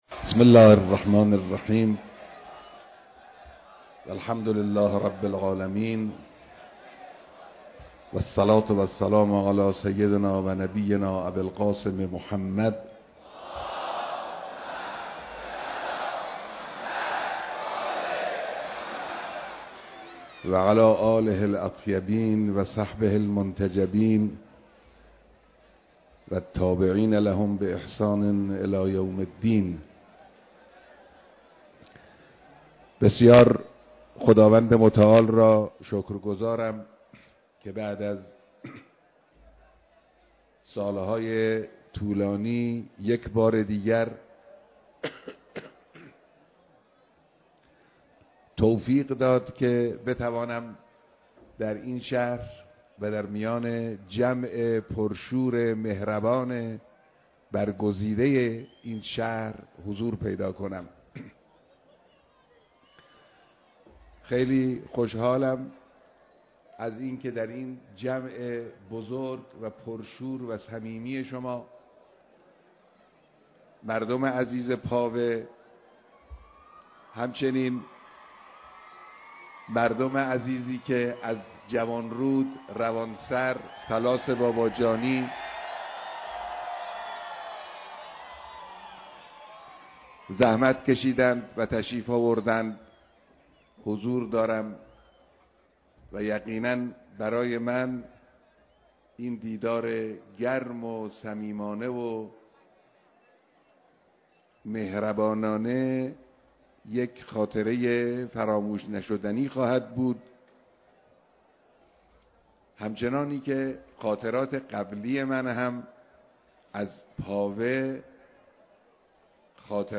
بیانات در دیدار مردم پاوه